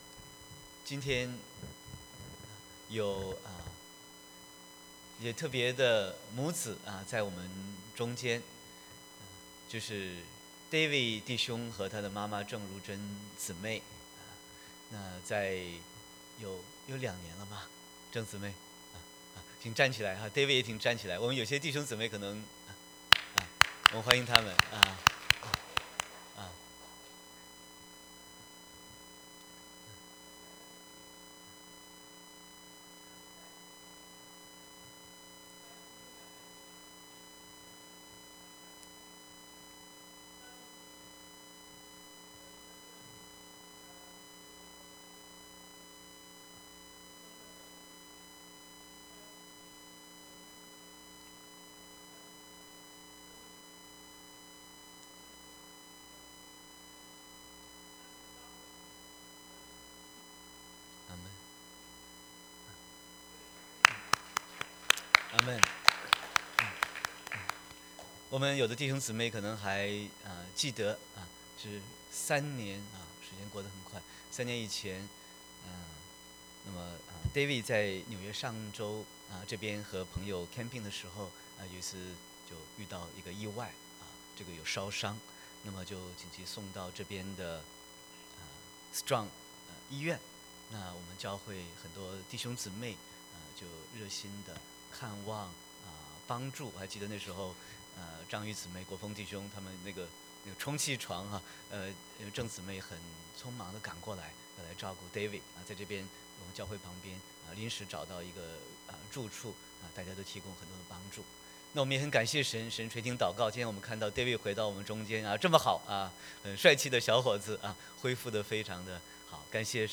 Sermon 03/24/2019